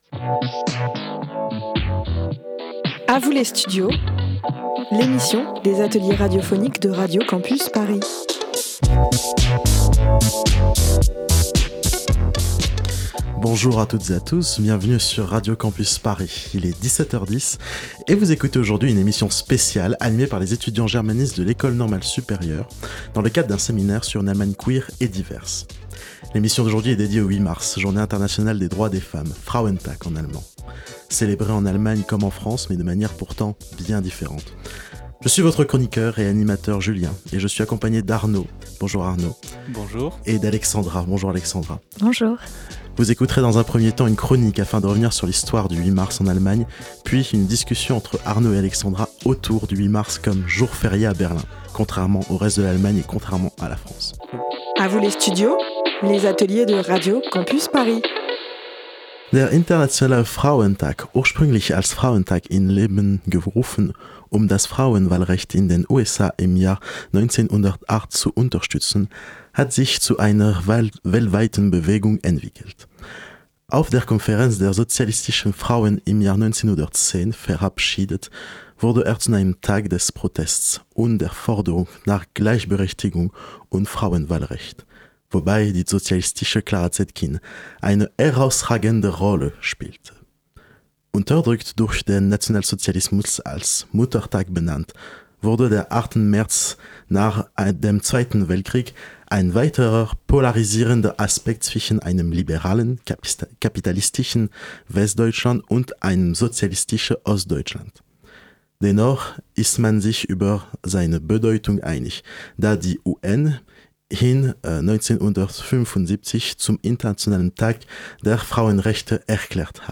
Une émission des élèves germanistes de l'École N...